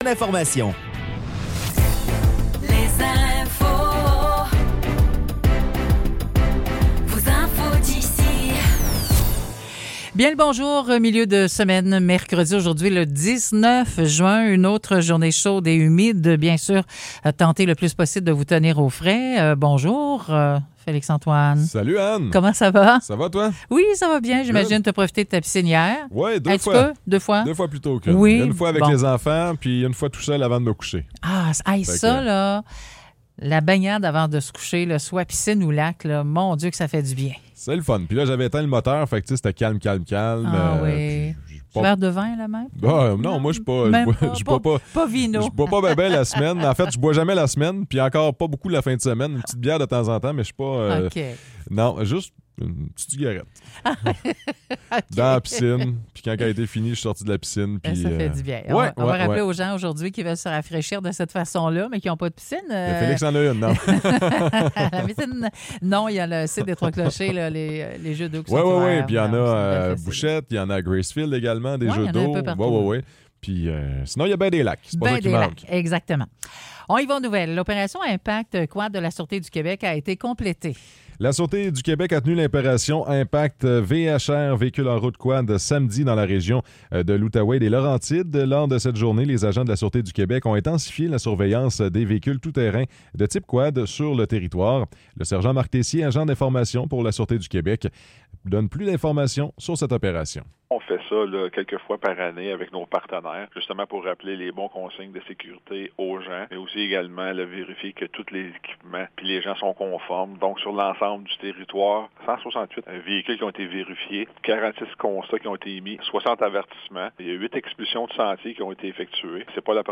Nouvelles locales - 19 juin 2024 - 9 h